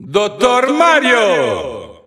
Italian Announcer announcing Dr. Mario.
Dr._Mario_Italian_Announcer_SSBU.wav